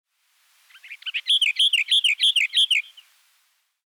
Klapperstrophe
kurz (?/i) lang (?/i) Klappergrasmücke
Sylvia_curruca_TSA-short.mp3